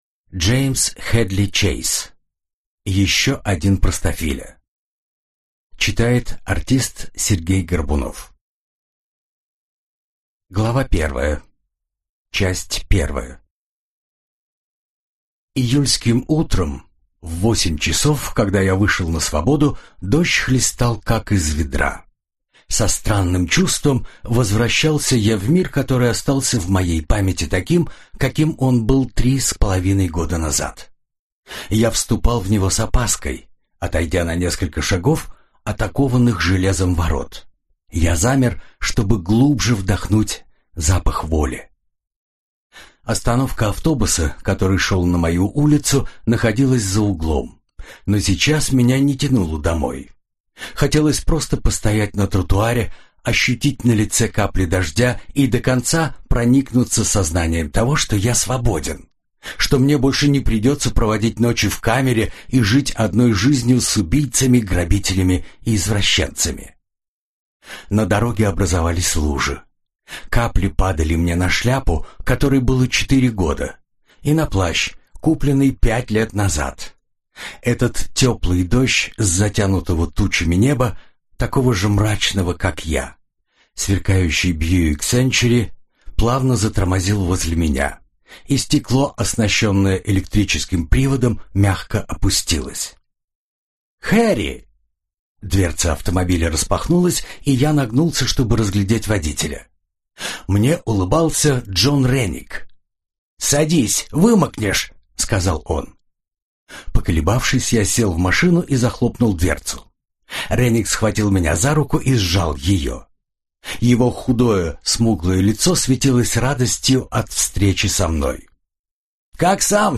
Аудиокнига Еще один простофиля | Библиотека аудиокниг